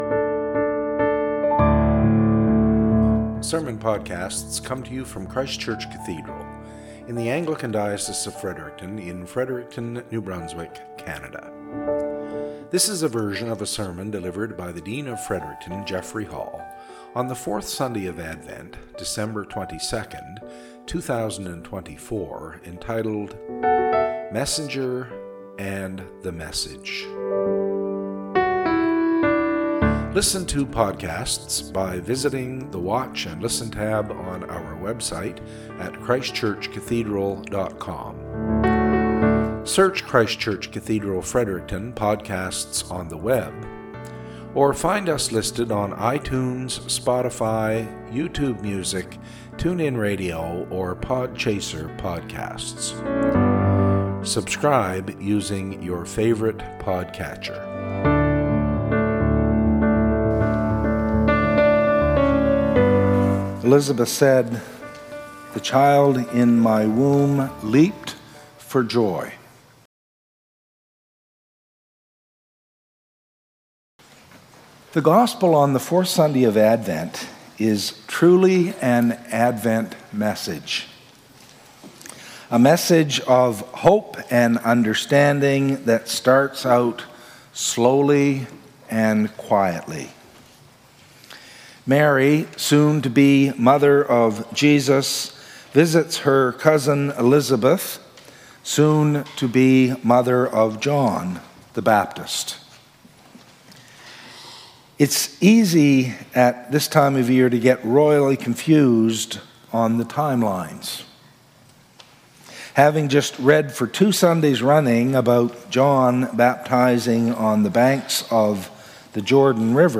Cathedral Podcast - SERMON -